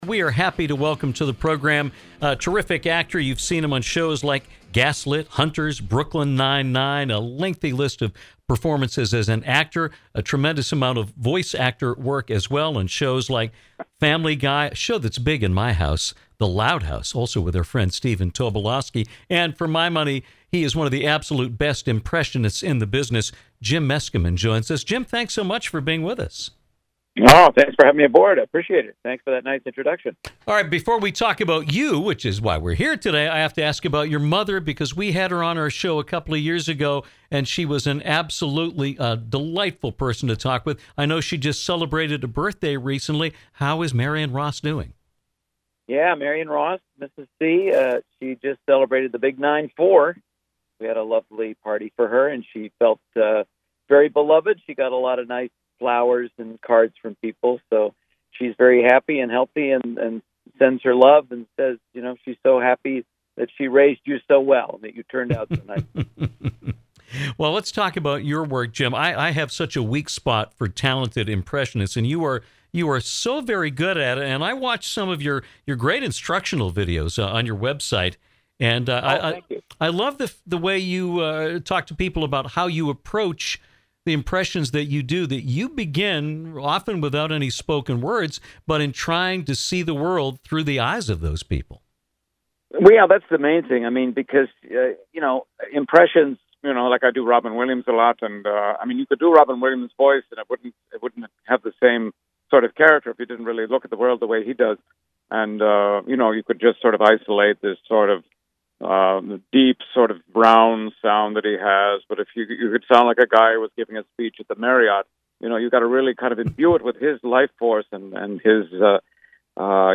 Actor and impressionist Jim Meskimen joined us to share some of his brilliant impressions and talk about his work on GASLIT, HUNTERS, BROOKLYN NINE-NINE, and more.